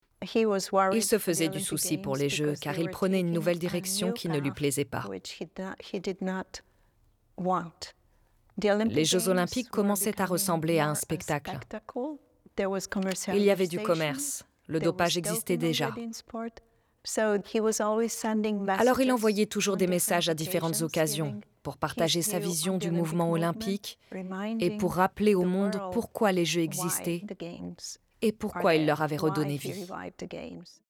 Voix off
Voix Femme Age Mûr - Doc Pierre de Coubertin